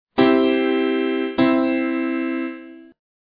C major chords
In the second chord, the dominant becomes optional because it has just been heard.